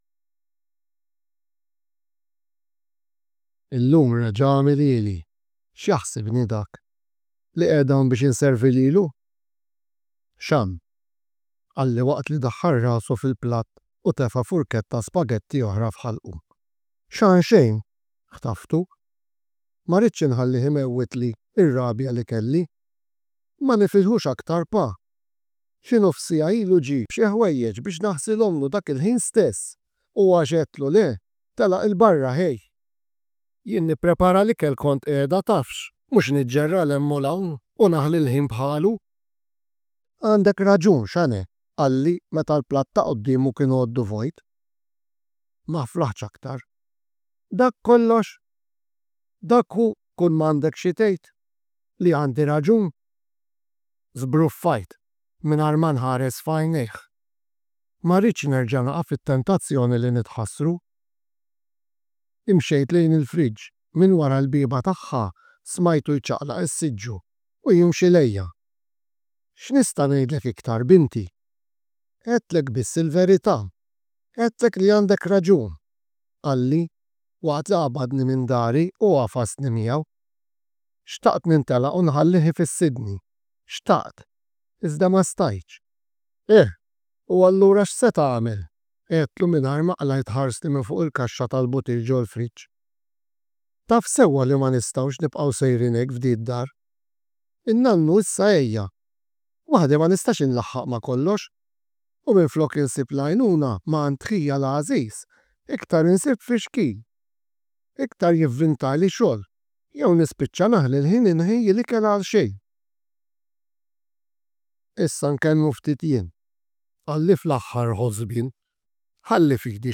Kull taħriġ jinkludi s-silta moqrija, il-karta tat-taħriġ għall-istudenti u l-karta tal-għalliema bir-risposti.